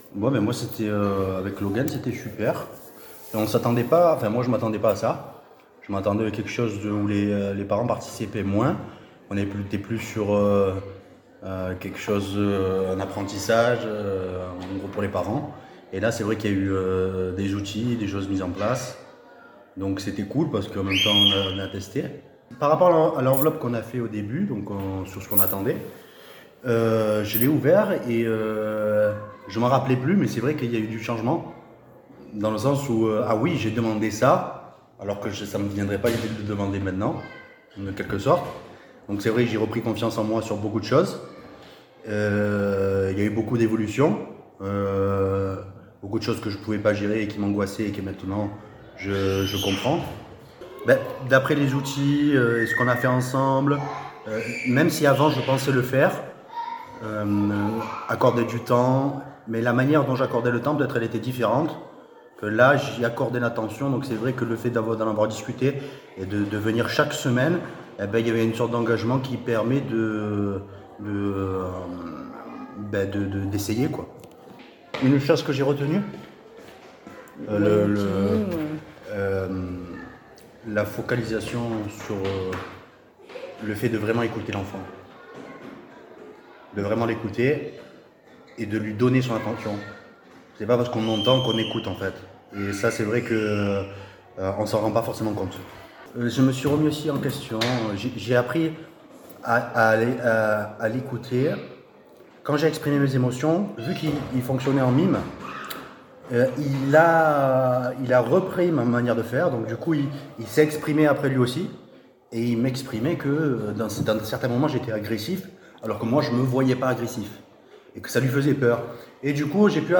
Cliquez ici pour écouter le témoignage audio de deux parents participants.